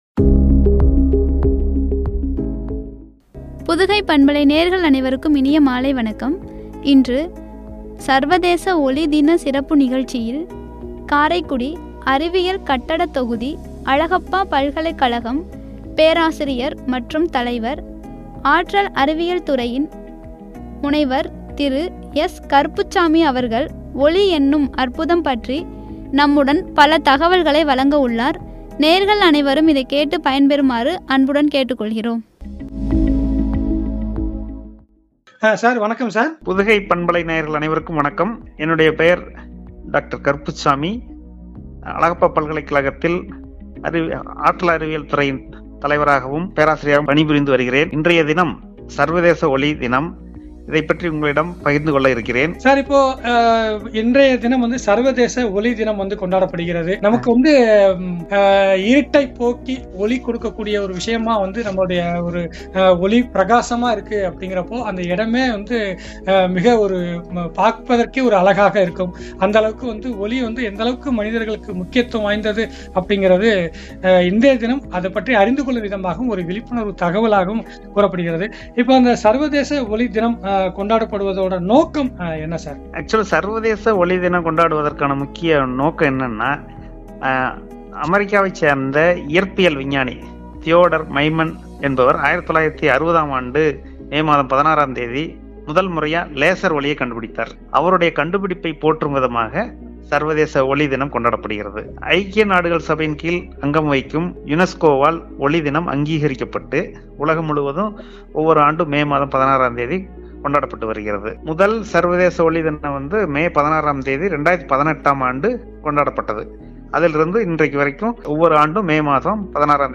“ஒளி என்னும் அற்புதம்” என்ற தலைப்பில் வழங்கிய உரையாடல்.